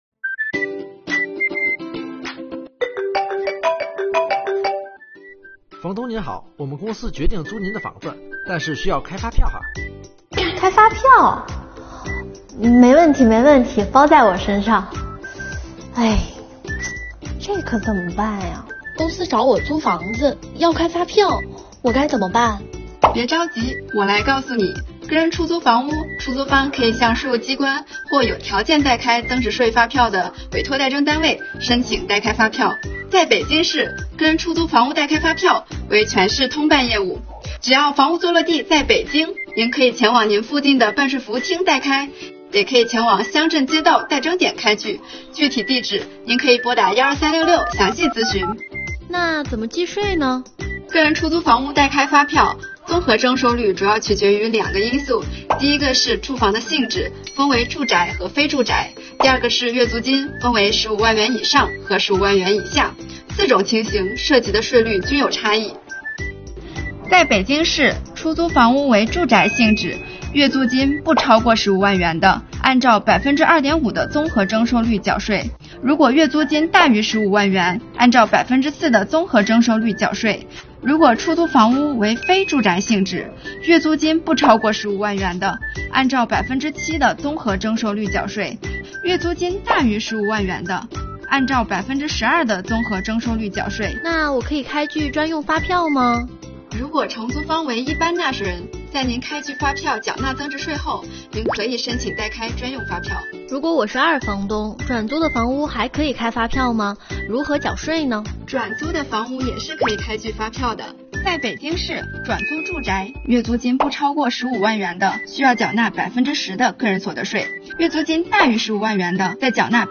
标题: 个人出租房屋如何缴税开票？听女税官详细解答